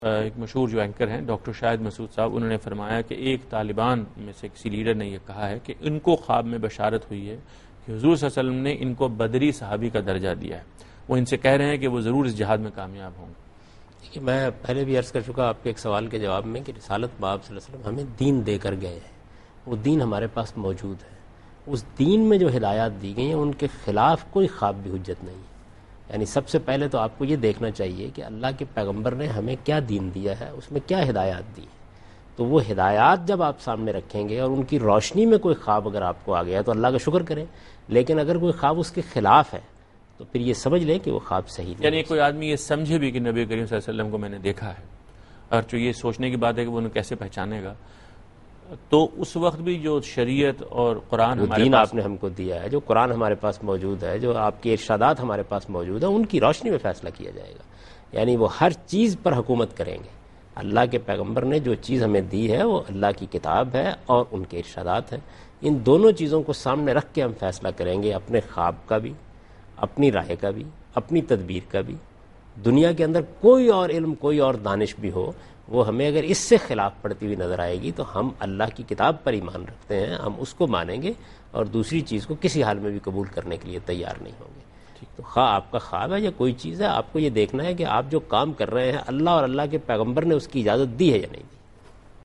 Category: TV Programs / Dunya News / Deen-o-Daanish /
Answer to a Question by Javed Ahmad Ghamidi during a talk show "Deen o Danish" on Dunya News TV